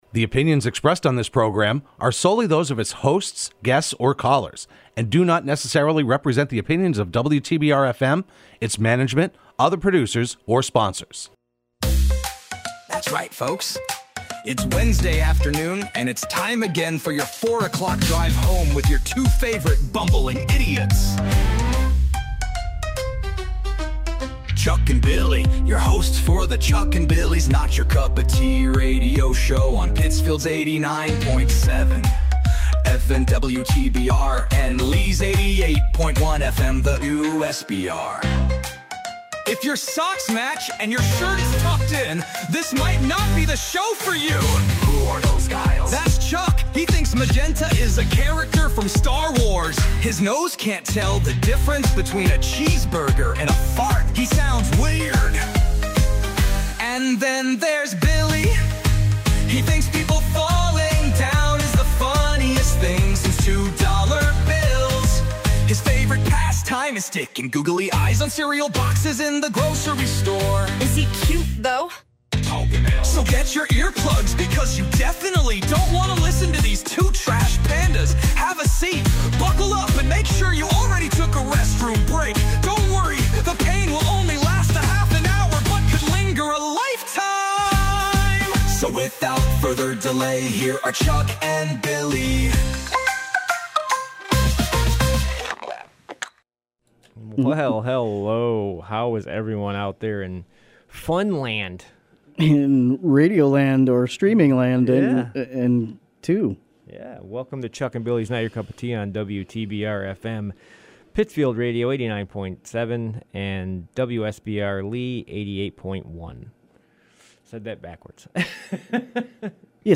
Broadcast live every Wednesday afternoon at 4pm on WTBR.